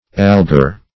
Search Result for " algor" : The Collaborative International Dictionary of English v.0.48: algor \al"gor\ ([a^]l"g[o^]r), n. [L.]